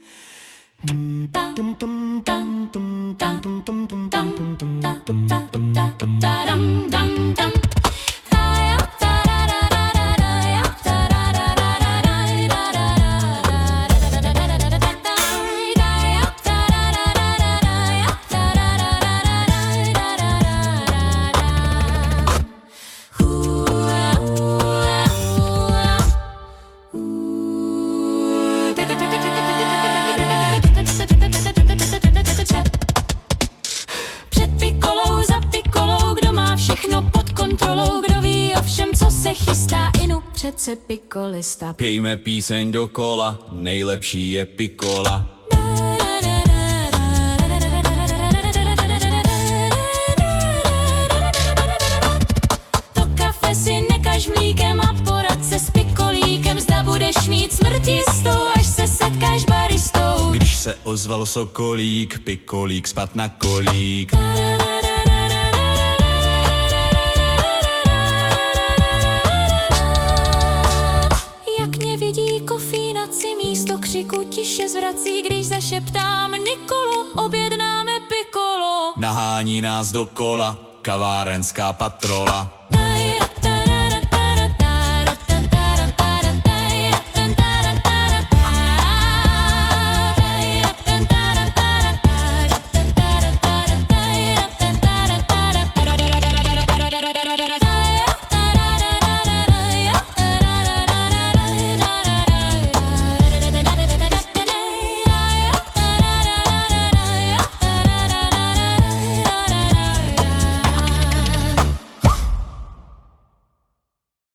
AI zhudebněno tady...